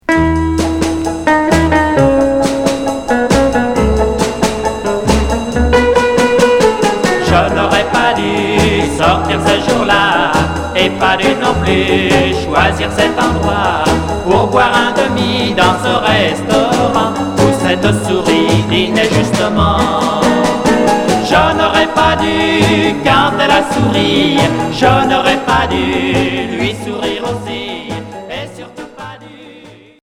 Twist